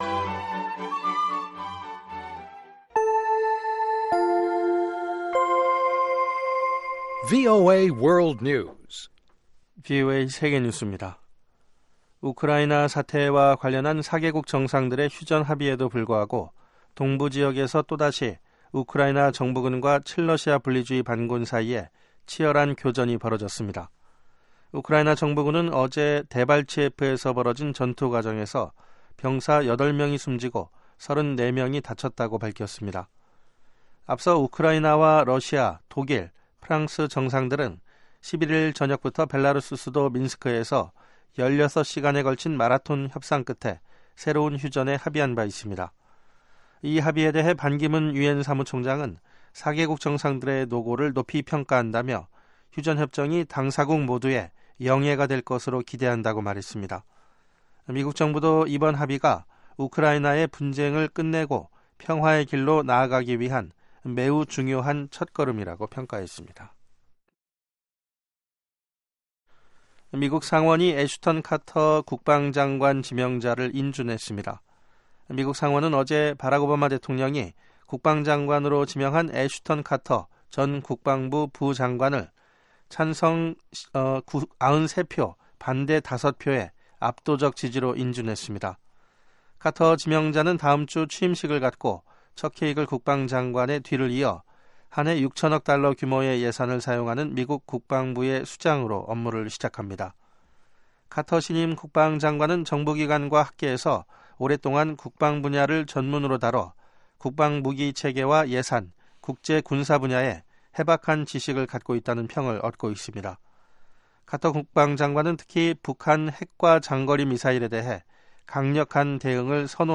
VOA 한국어 방송의 시사 교양 프로그램입니다.